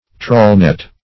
Trawlnet \Trawl"net`\, n.
trawlnet.mp3